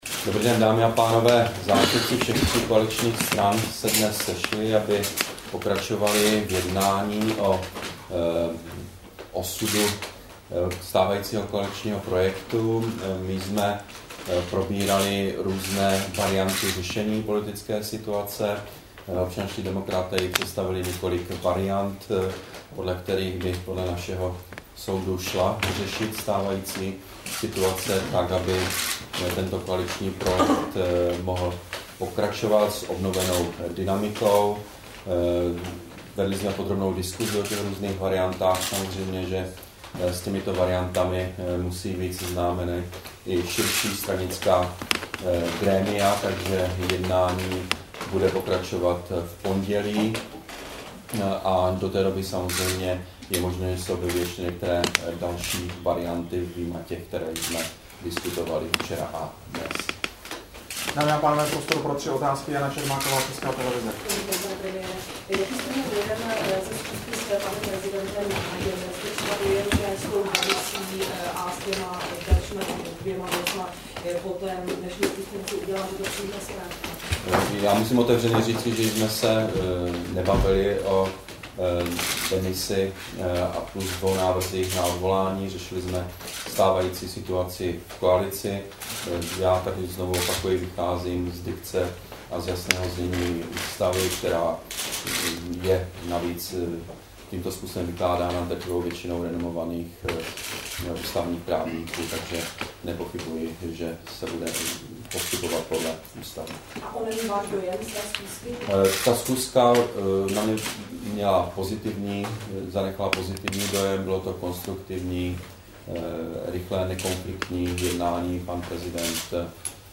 Brífink premiéra k aktuální politické situaci po jednání v Kramářově vile, 14. dubna 2011